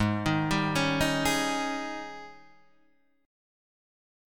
G#M7sus4 chord